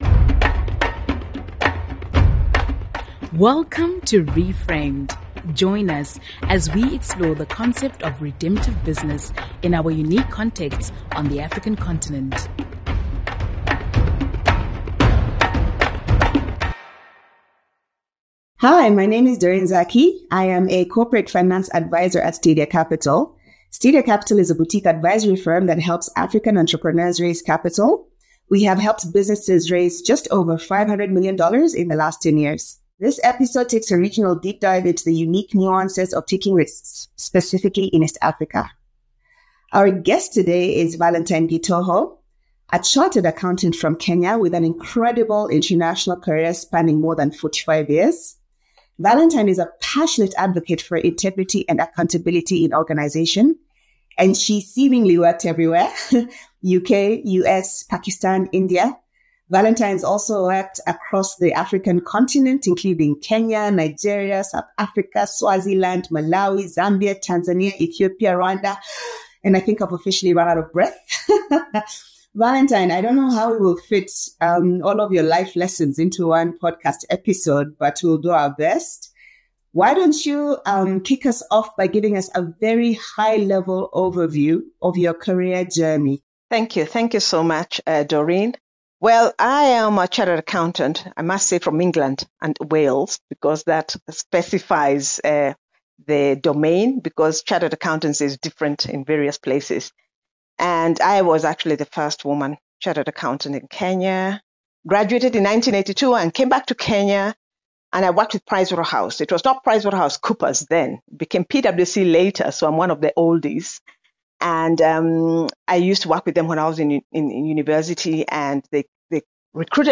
In each series youll hear from business leaders as they explore the ways that we can fundamentally shape industry and transform society through Biblically-aligned practices.